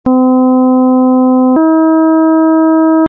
ἀρχεῖο ἤχου Νη-Βου· ὁ Νη στὰ 256Hz]
Ni-Bou_256Hz.mp3